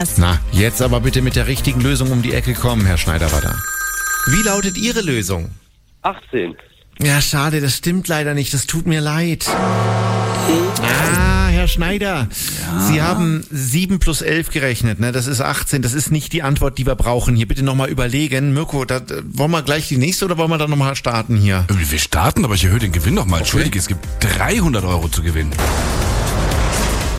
Aber die ganze Art, wie die beiden Moderatoren auf die nach einem Telefonklingeln eingespielten Antworten reagieren, erweckt den Eindruck, da hätte gerade jemand angerufen.
falschantwort.m4a